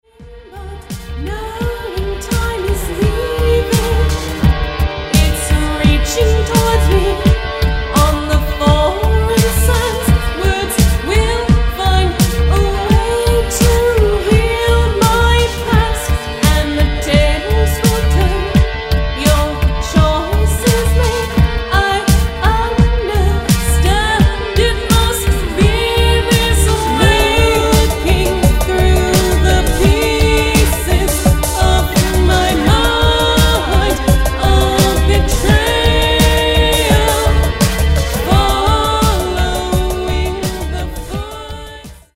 gothic/darkwave duo